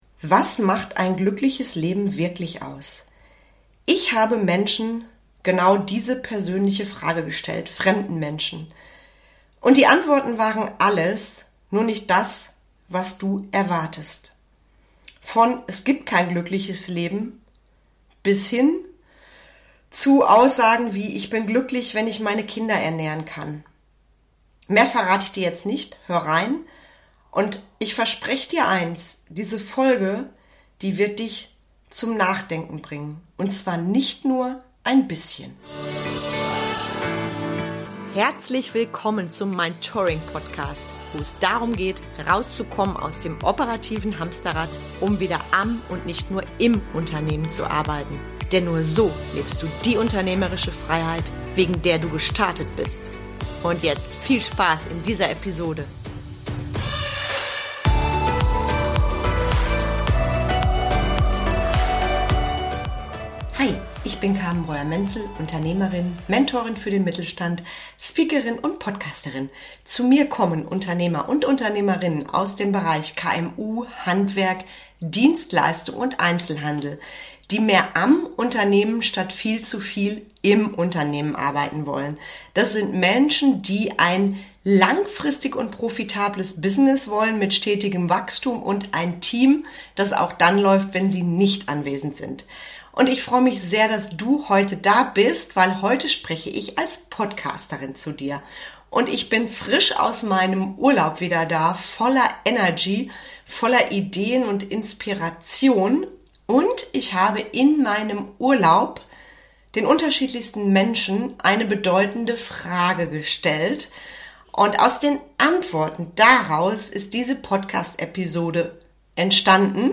Folge #285: Was macht ein glückliches Leben aus – wirklich? In dieser Podcast-Folge teile ich echte Antworten von Menschen aus Gesprächen in der Karibik.